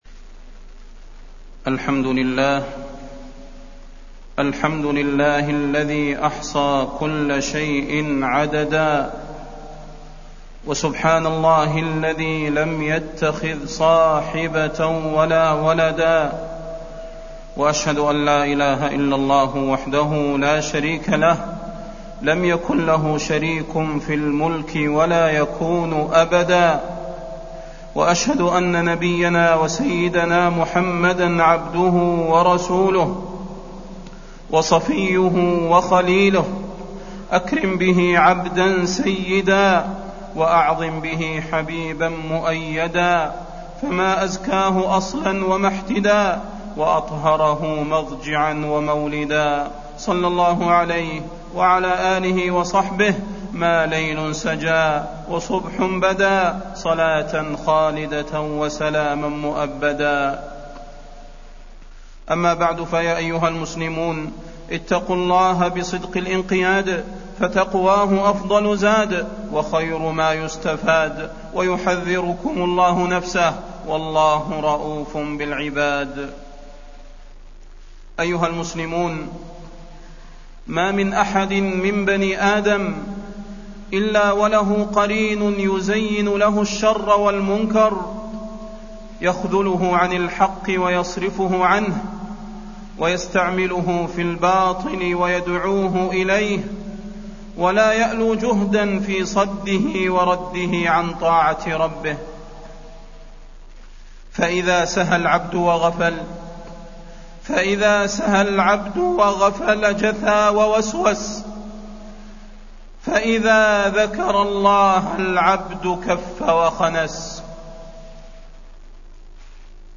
تاريخ النشر ٢١ شوال ١٤٢٨ هـ المكان: المسجد النبوي الشيخ: فضيلة الشيخ د. صلاح بن محمد البدير فضيلة الشيخ د. صلاح بن محمد البدير تزيين القرين